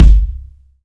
Kick Rnb 3.wav